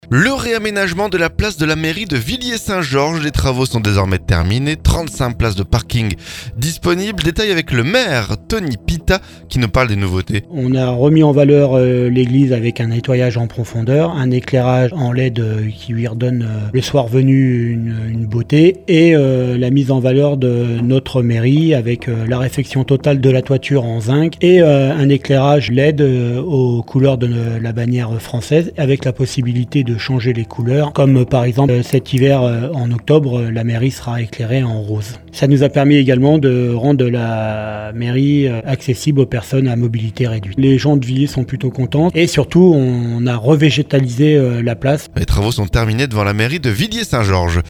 Les travaux sont désormais terminés. 35 places de parking sont disponibles. Détails avec le maire de Villiers Saint Georges Tony Pita nous parle des nouveautés.